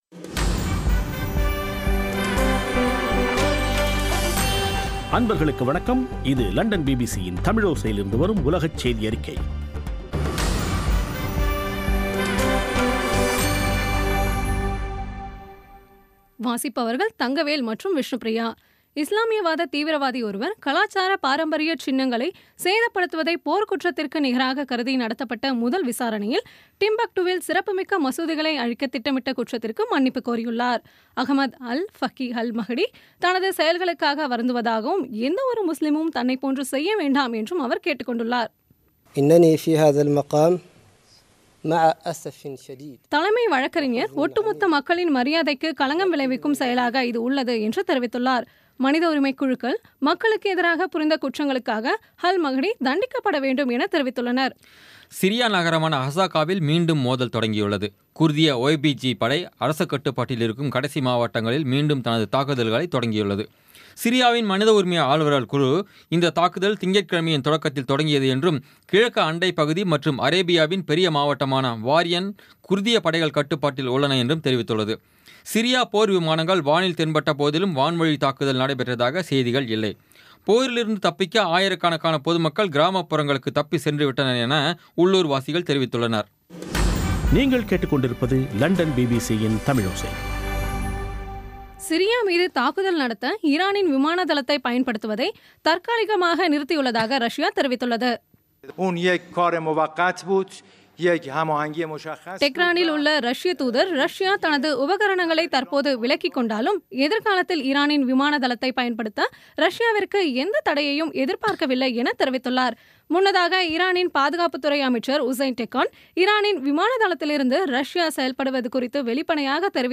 பி பி சி தமிழோசை செய்தியறிக்கை (22/08/2016)